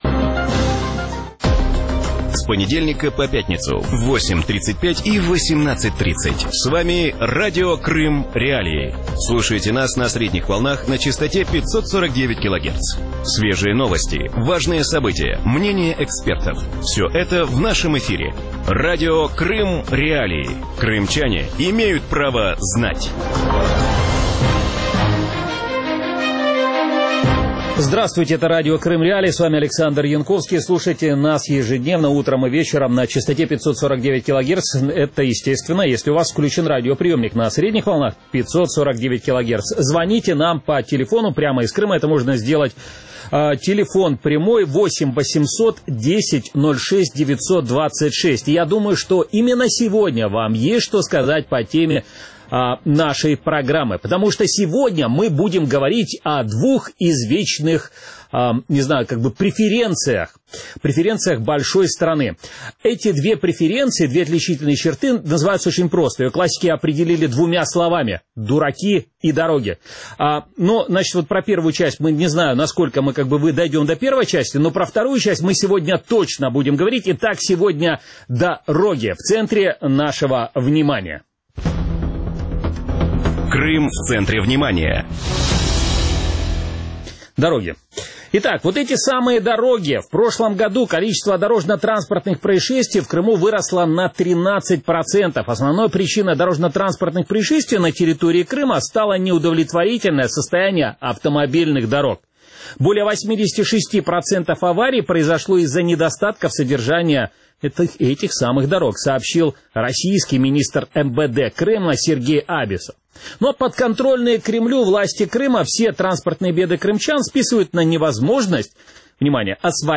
В вечернем эфире Радио Крым.Реалии обсуждают состояние дорожного полотна в Крыму и действия российских властей по его восстановлению.